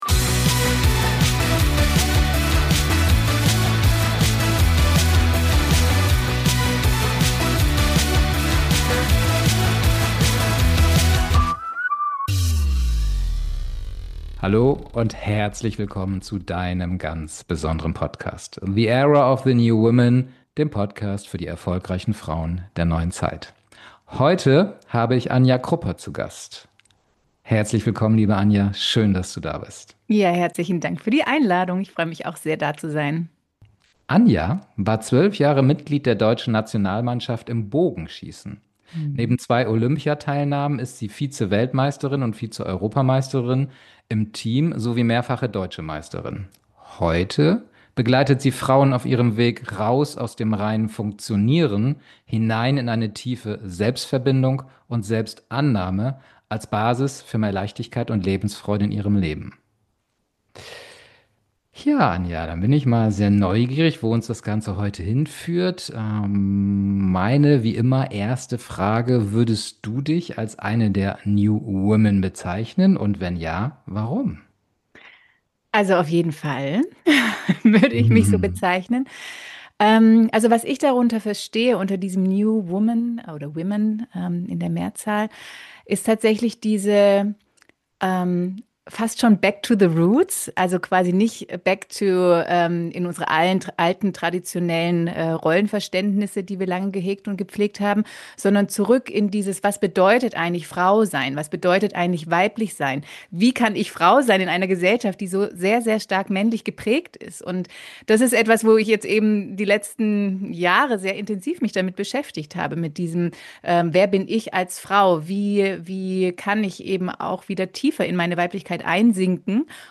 #062 Vom Funktionieren zur Selbstverbindung. Das Interview